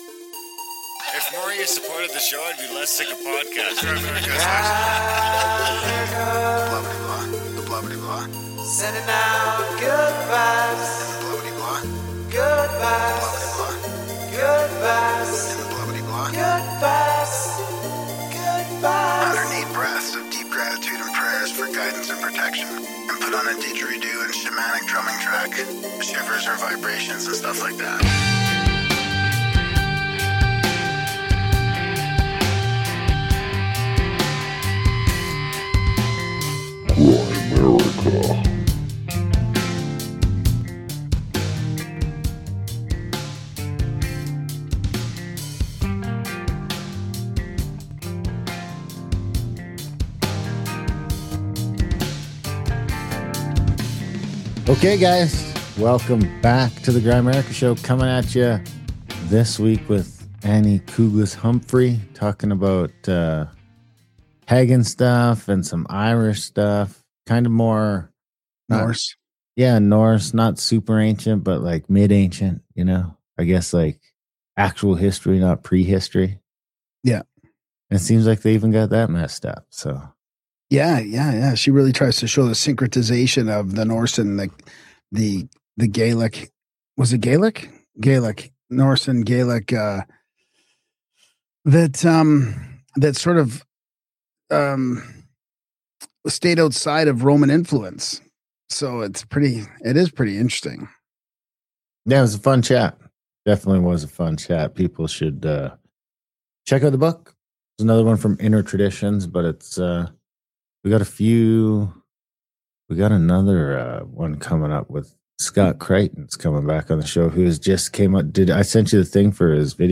Interview starts at 34:10